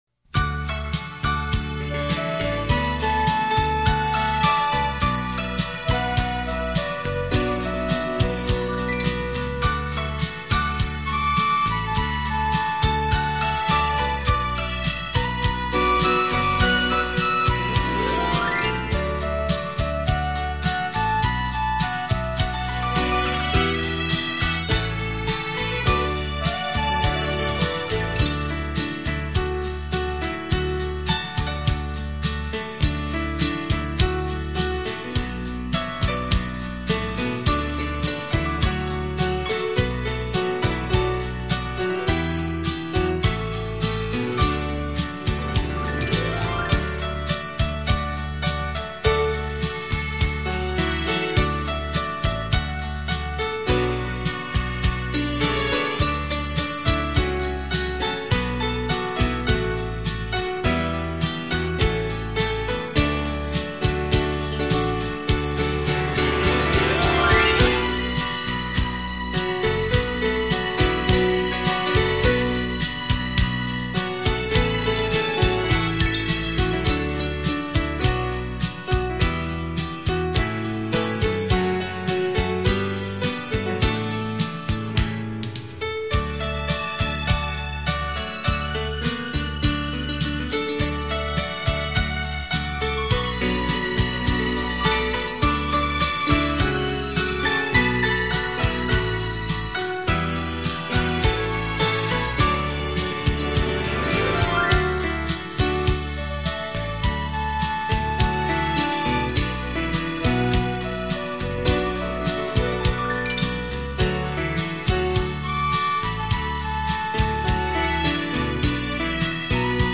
百万双钢琴演奏曲 国语精选
轻盈的钢琴独奏撼动人心 激荡出幽幽淡淡的思乡情愁
优美滑动的旋律 夹杂耐人寻味复杂的思绪于其中
以琴声解码爱情的极致美学 体验钢琴静谧 激情的双面美感